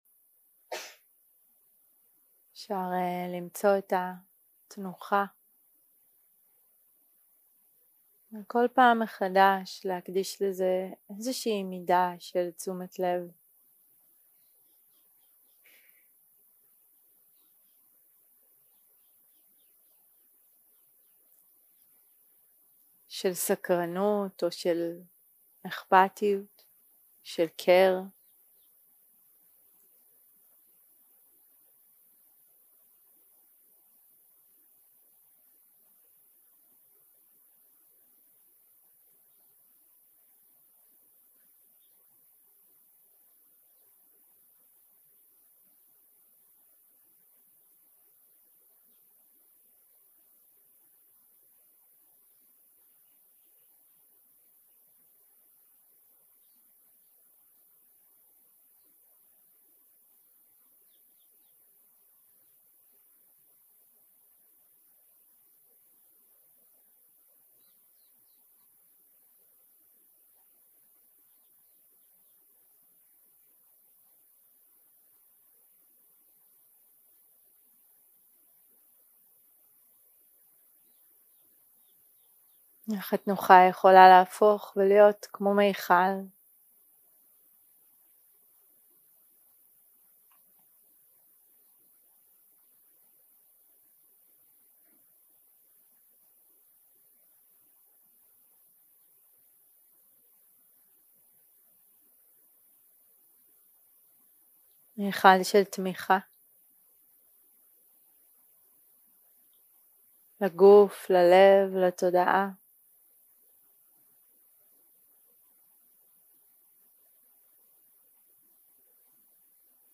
יום 5 - הקלטה 10 - צהרים - מדיטציה מונחית - מטא ושמחה
Dharma type: Guided meditation שפת ההקלטה